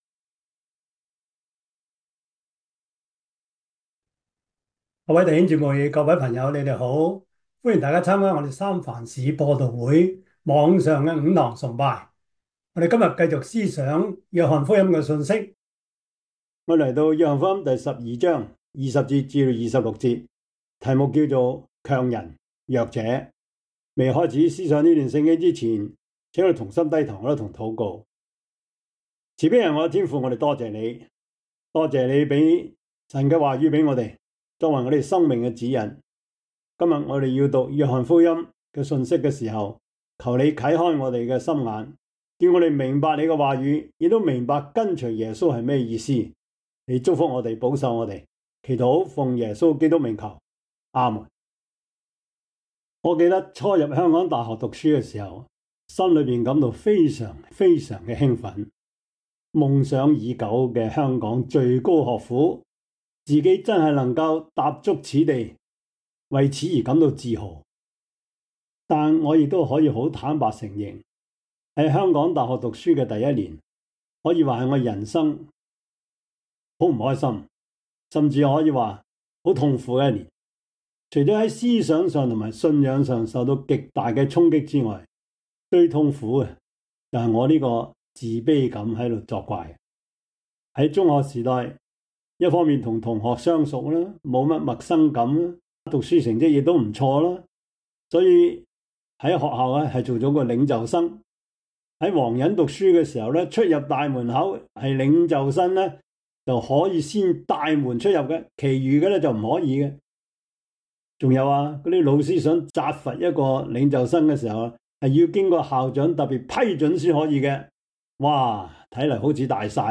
約翰福音 12:20-26 Service Type: 主日崇拜 約翰福音 12:20-26 Chinese Union Version